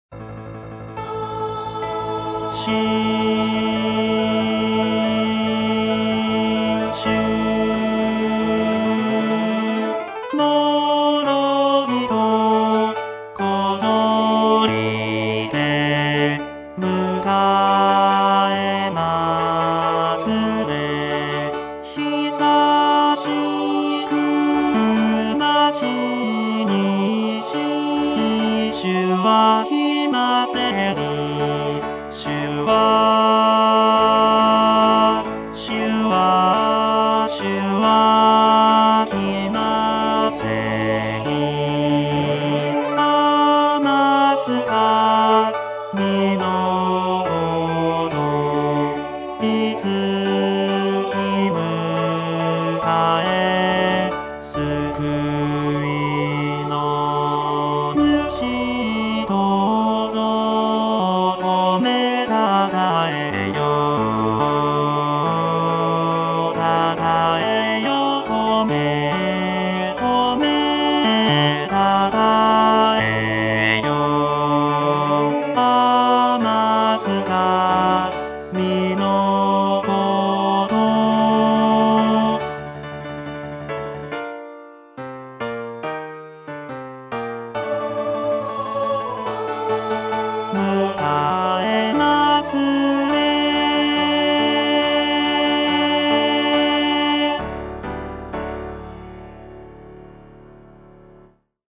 浦和ｗ用　4パート編曲版　テンポ遅い　練習用
バス（歌詞付き）